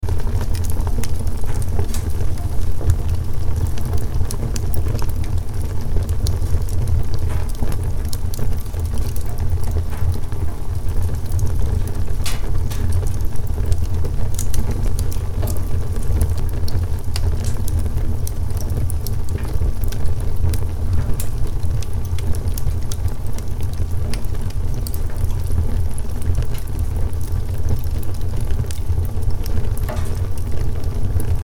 Crackling Fire Noise
Relax with the soothing sound of a crackling fireplace, perfect for creating a warm, cozy vibe during winter.
Let the gentle crackle of burning wood bring winter comfort to your space.
Genres: Sound Effects
Crackling-fire-noise.mp3